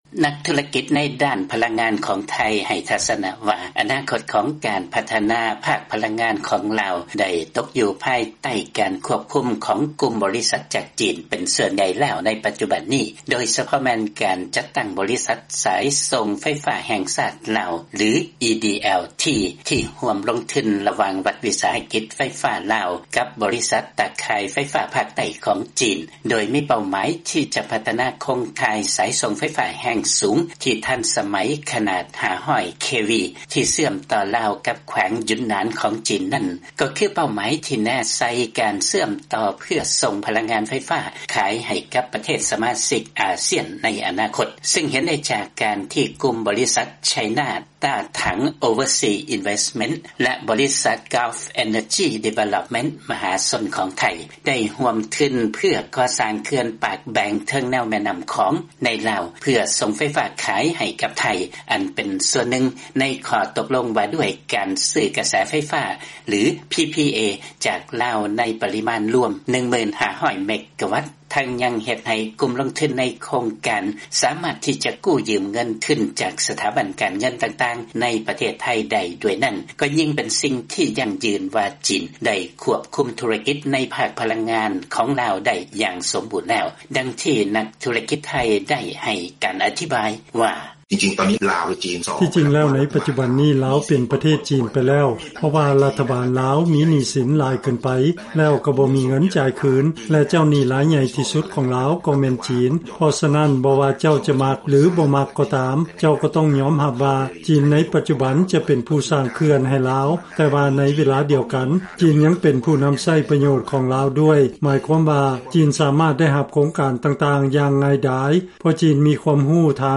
ລາຍງານ